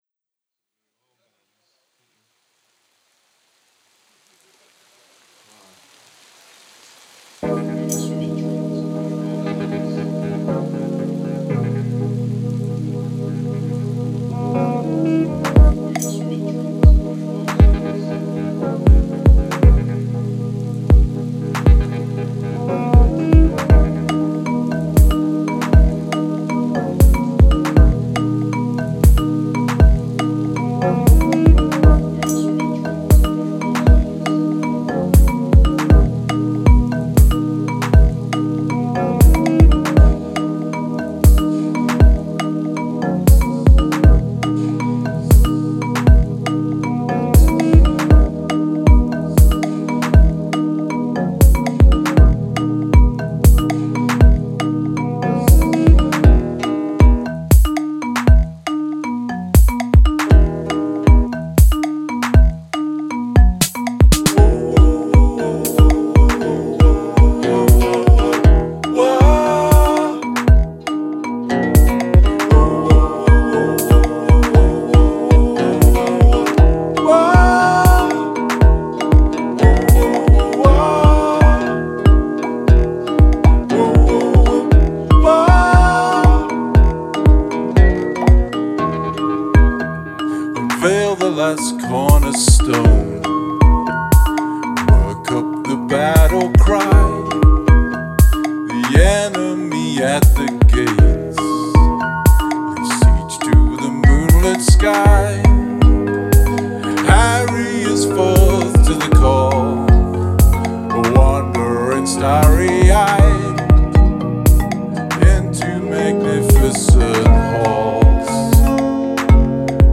A Remix of a recording we made of a band